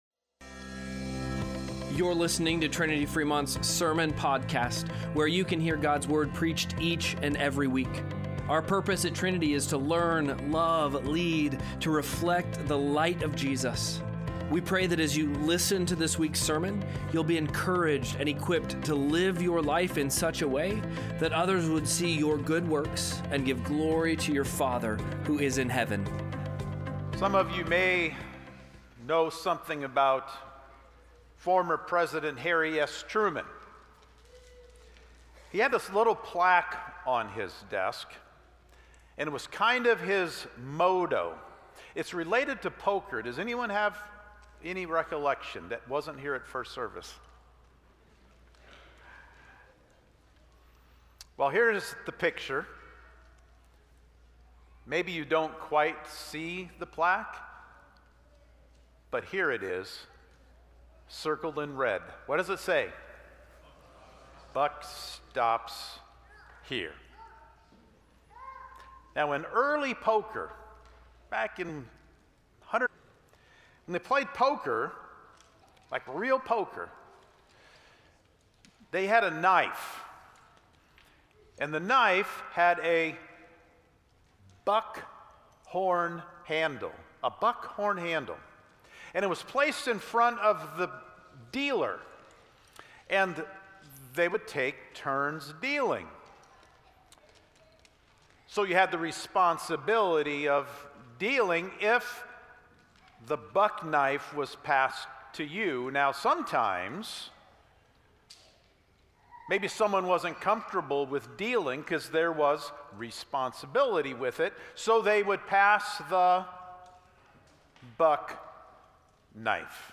2-22-Sermon-Podcast.mp3